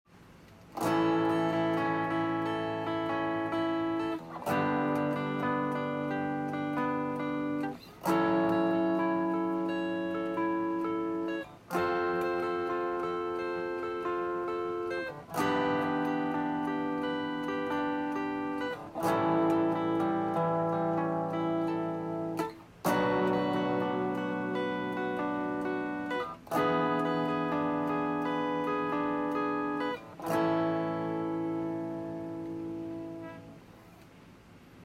さっきの曲で使ったコード進行。
1→7b→6m→6b→5m→1_7→2m→5